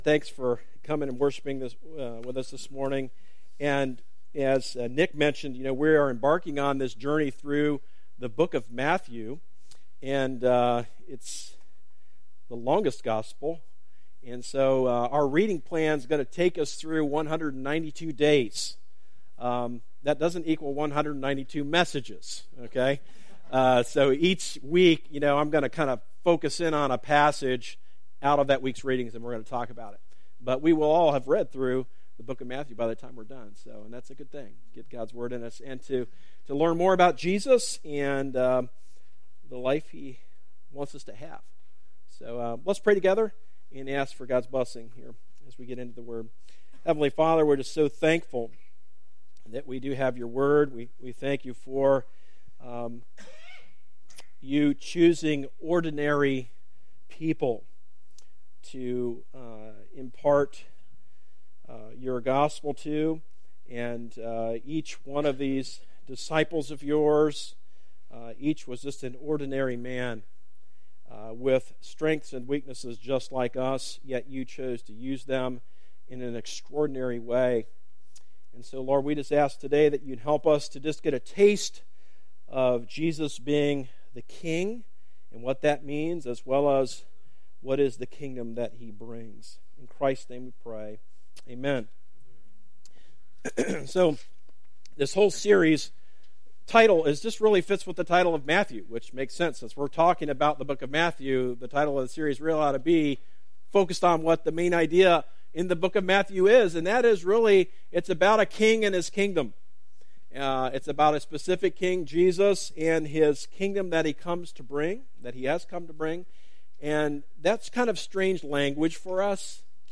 Recent Sermon - Darby Creek Church - Galloway, OH